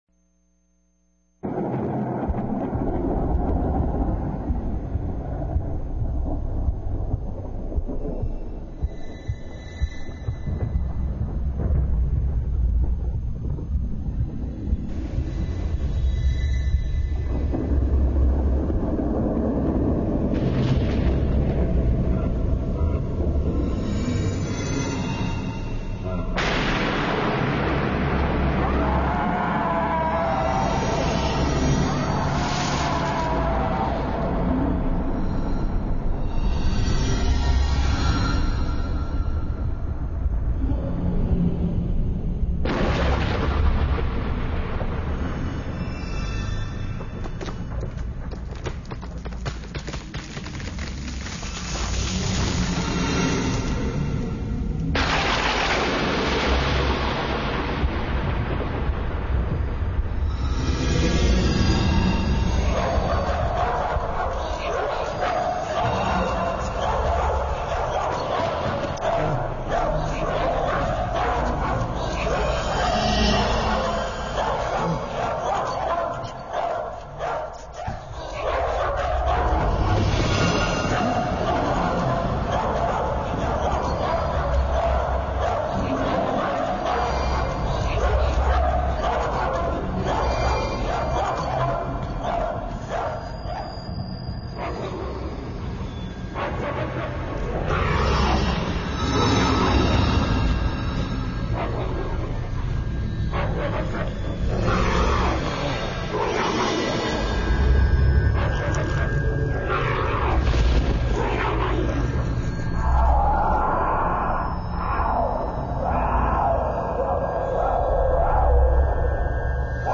Sound effects wavs
Halloween Sounds of horror
HalloweenSoundsofHorror.wav